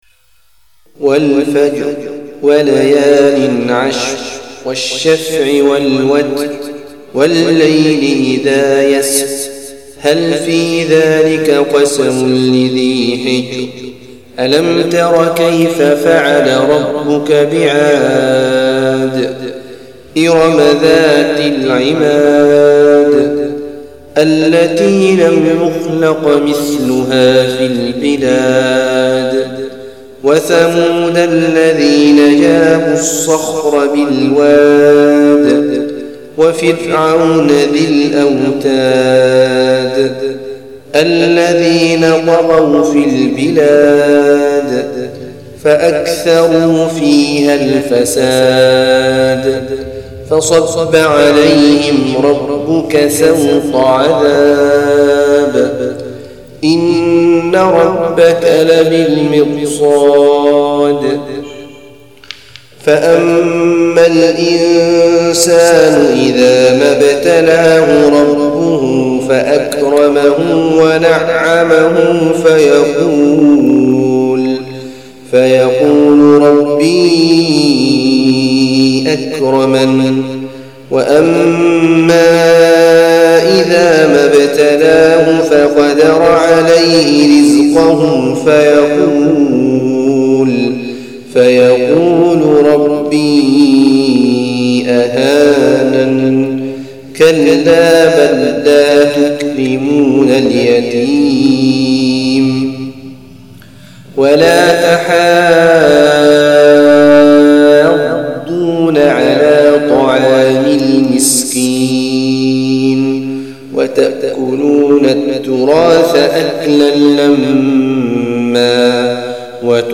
تلاوات مختارة